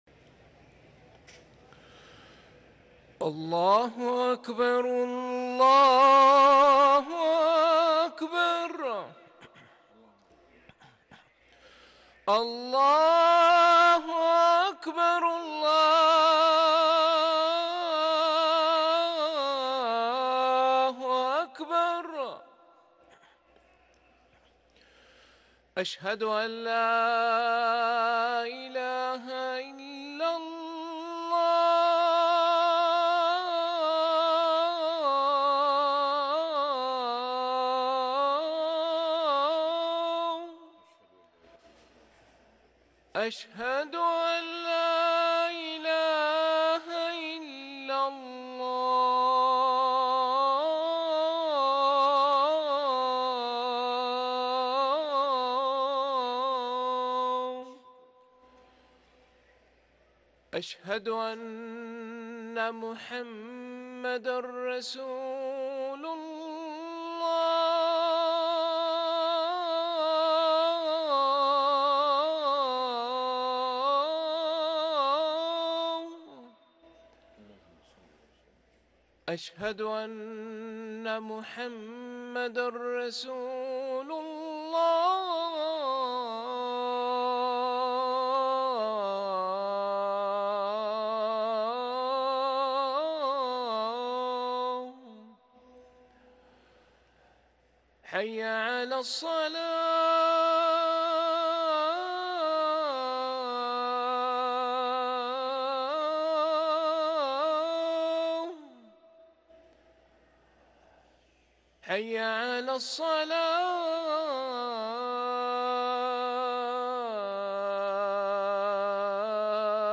اذان المغرب